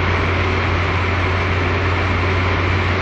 car rumble.wav